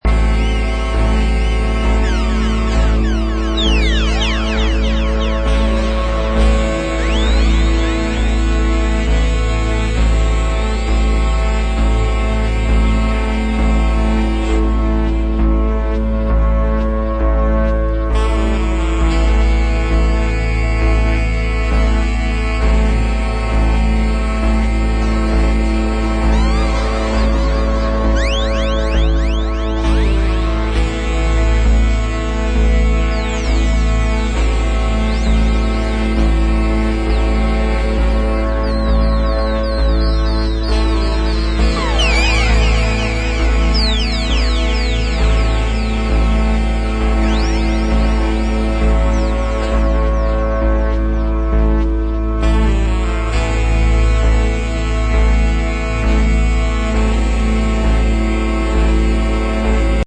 Trippy techno tracks
But it turned out as a really great electronic album.
Electronix Techno